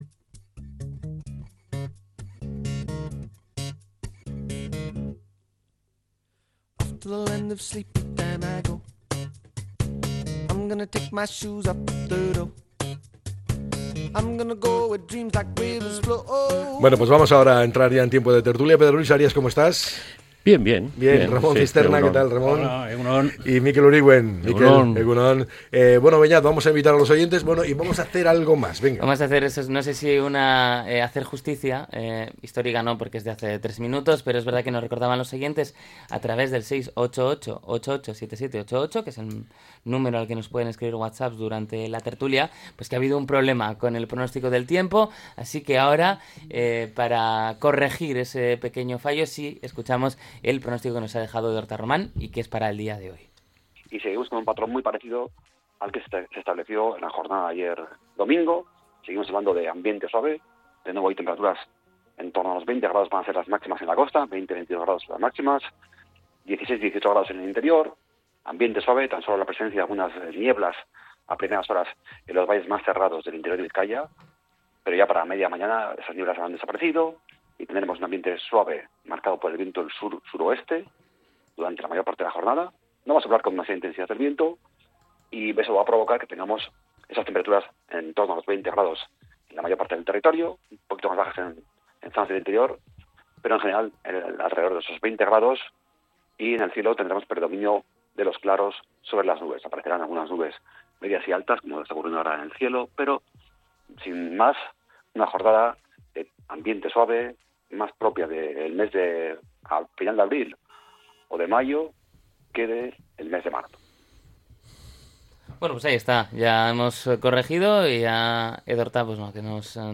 La Tertulia 02-03-26.